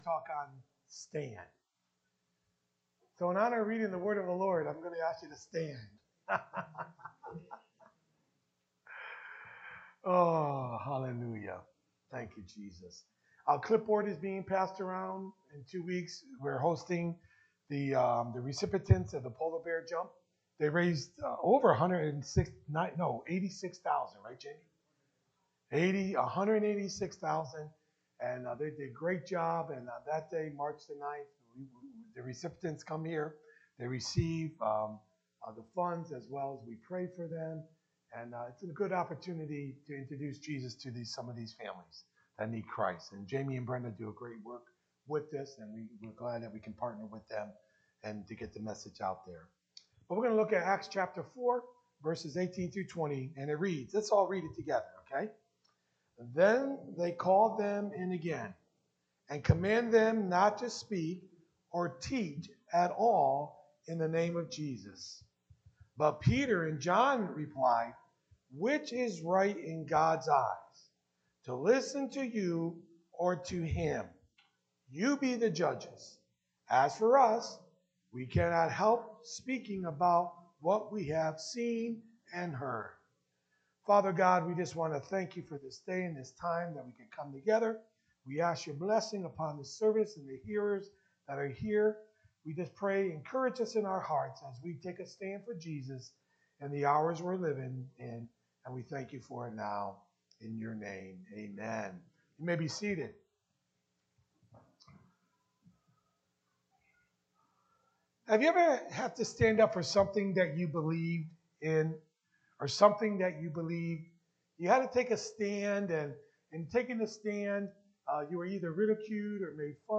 Sermons | Oneonta Assembly of God